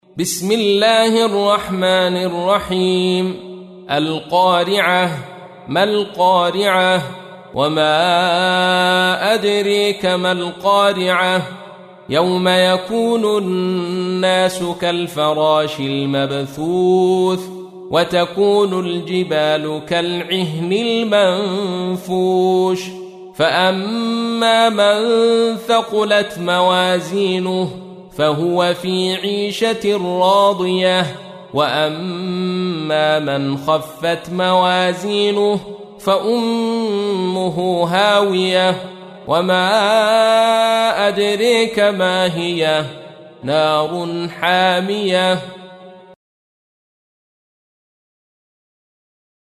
تحميل : 101. سورة القارعة / القارئ عبد الرشيد صوفي / القرآن الكريم / موقع يا حسين